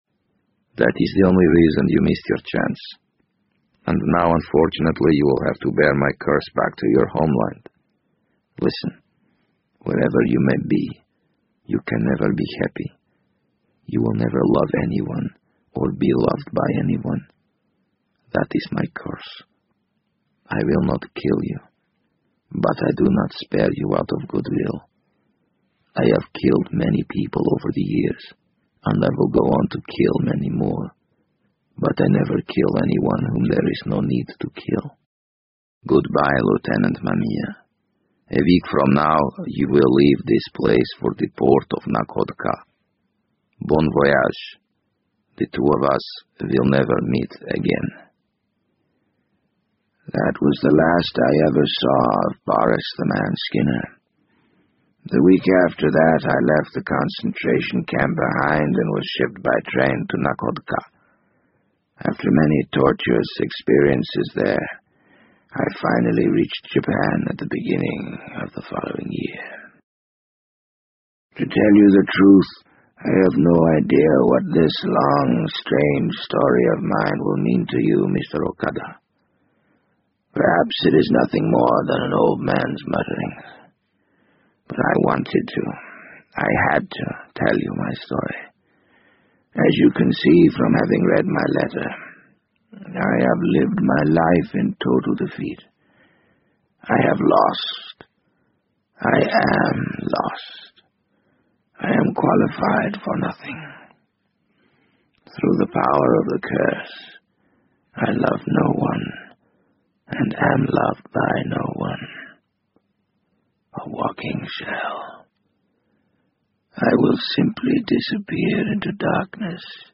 BBC英文广播剧在线听 The Wind Up Bird 014 - 19 听力文件下载—在线英语听力室